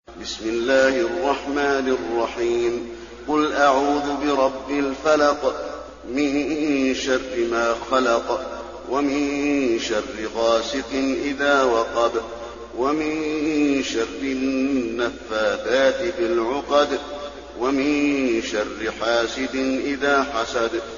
المكان: المسجد النبوي الفلق